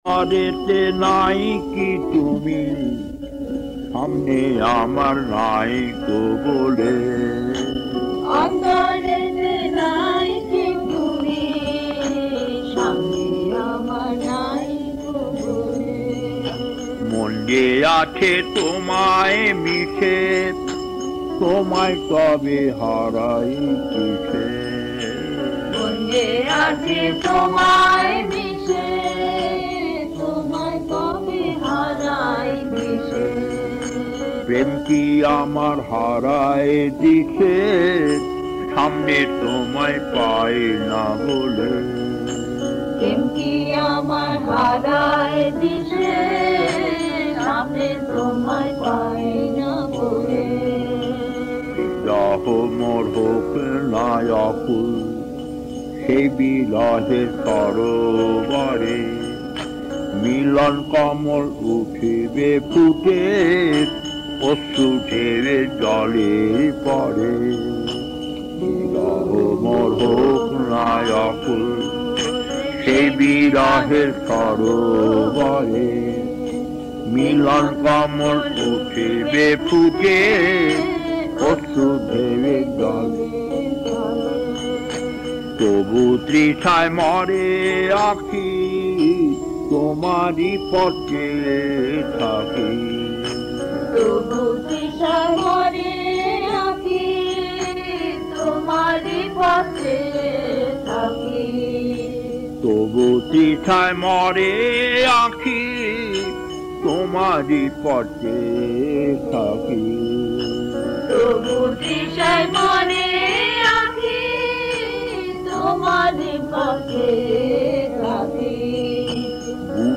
Kirtan C1-1 Chennai, mid 1980's, 38 minutes 1.